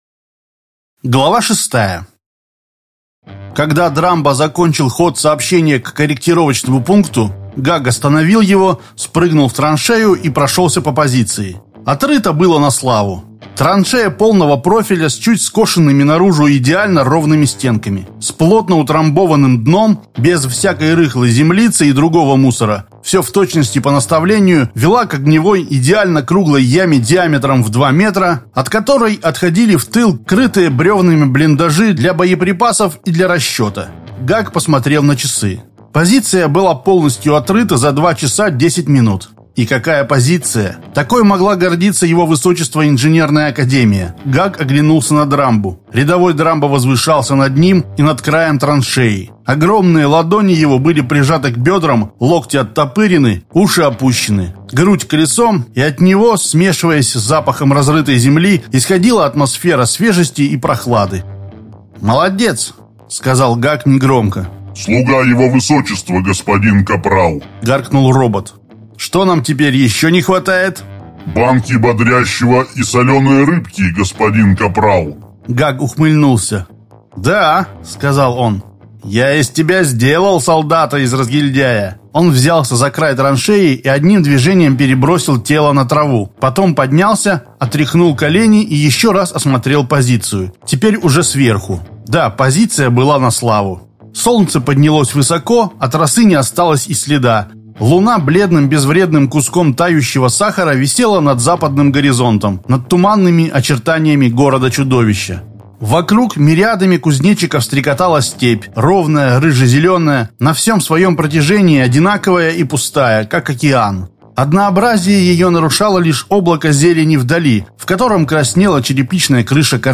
Аудиокнига Парень из преисподней. Часть 6.